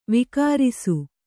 ♪ vikārisu